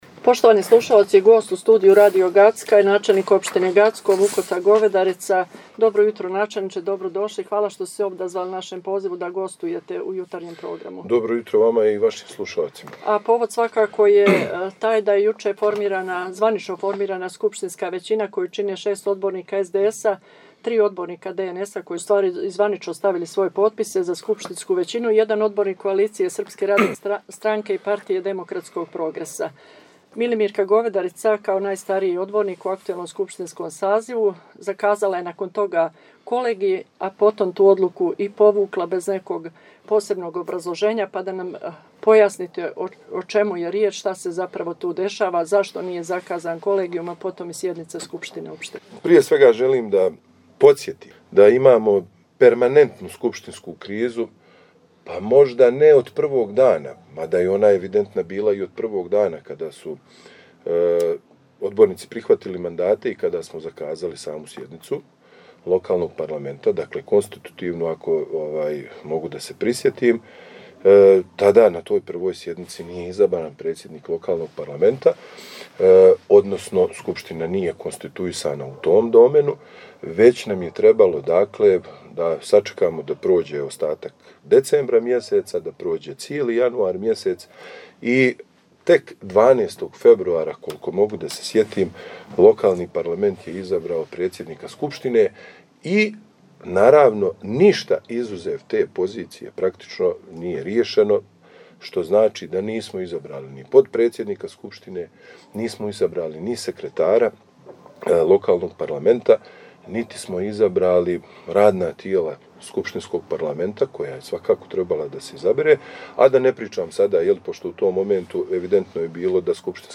Intervju sa načelnikom opštine Gacko Vukotom Govedaricom - Radio Gacko